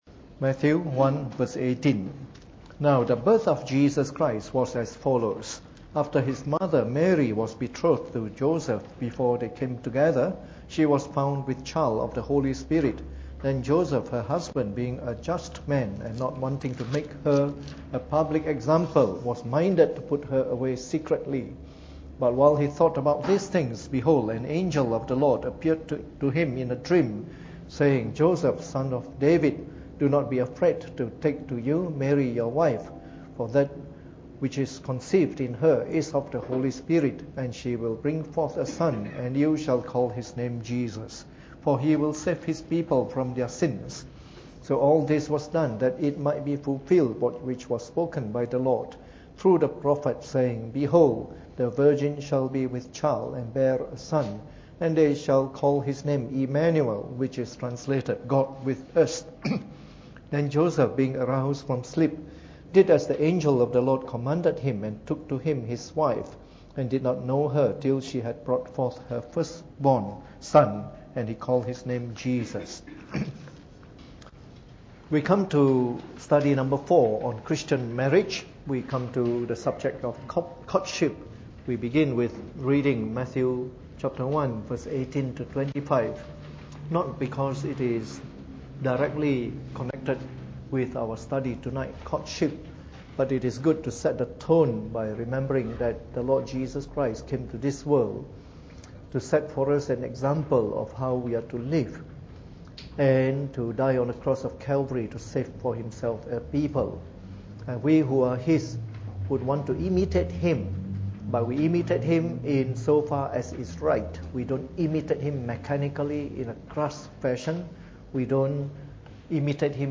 Preached on the 29th of July 2015 during the Bible Study, from our series on “Christian Marriage.”